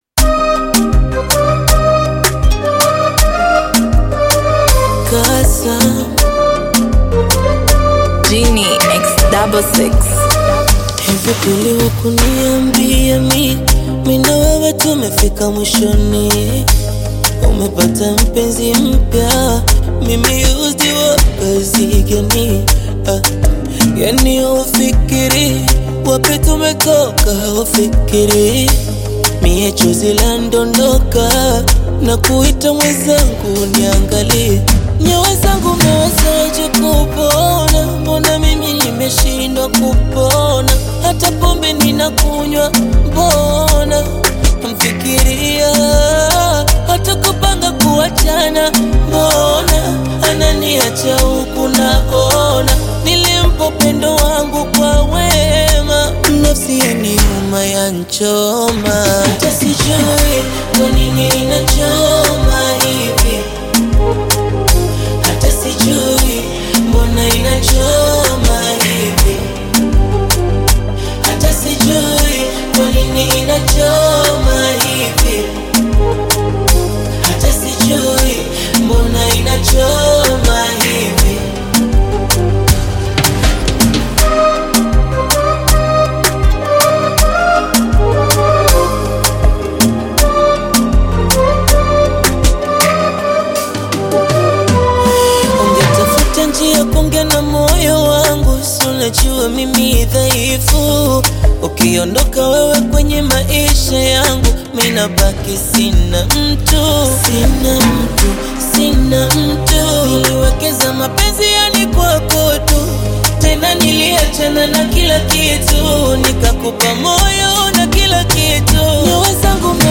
Tanzanian singer
blends smooth melodies with sincere lyrics